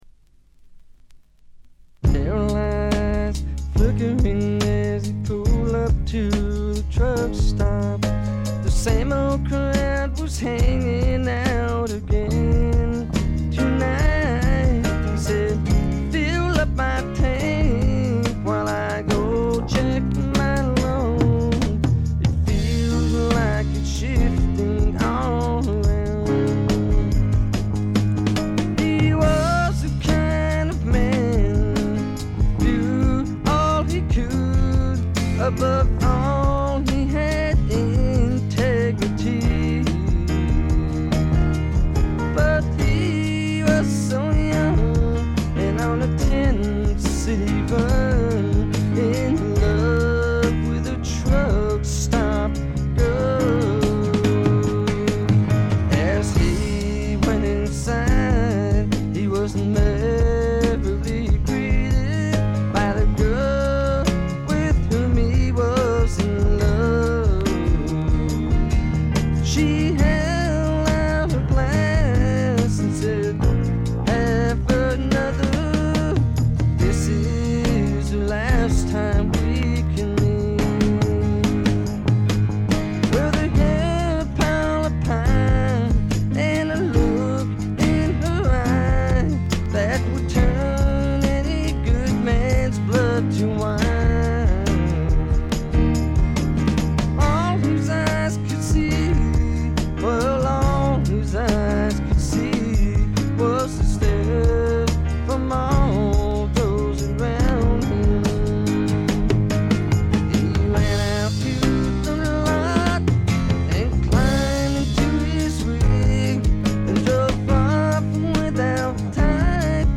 スタジオのCD面は軽微なチリプチ、散発的なプツ音が少々。
試聴曲は現品からの取り込み音源です。
[C](studio)